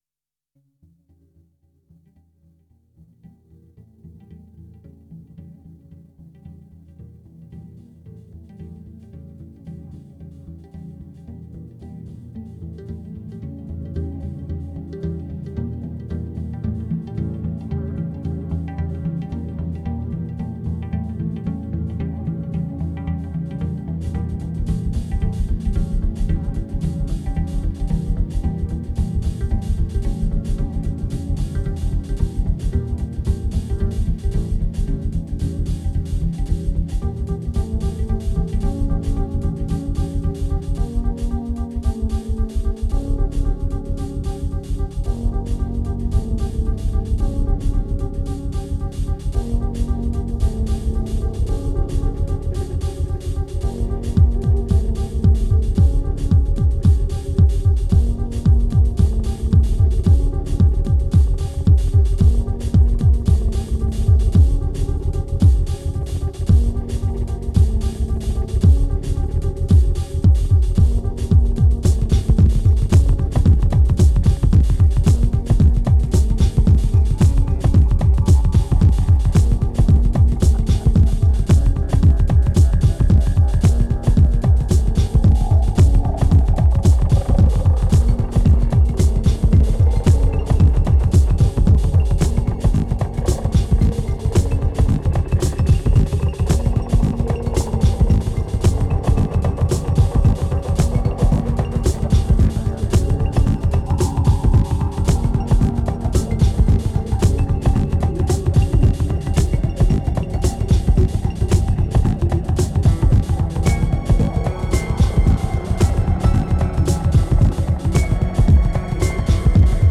ambient album
2374📈 - 13%🤔 - 112BPM🔊 - 2010-11-11📅 - -224🌟